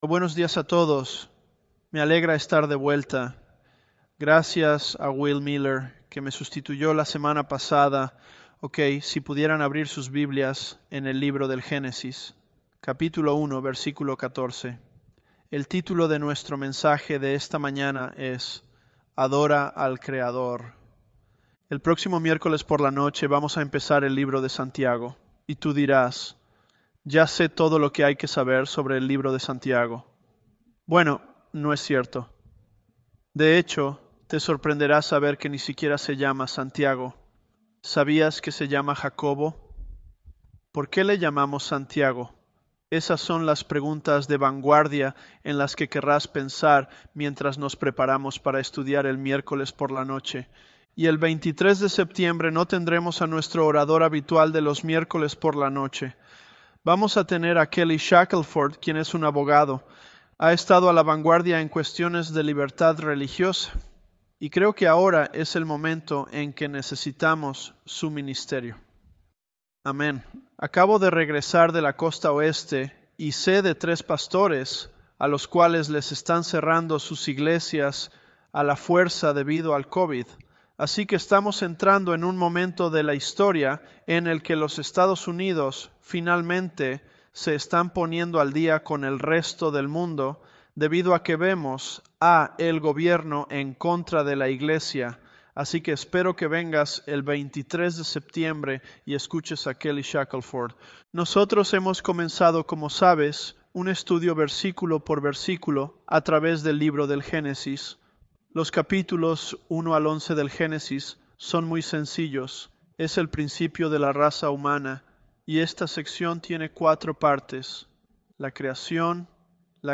ElevenLabs_Genesis-Spanish005.mp3